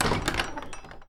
door_close.mp3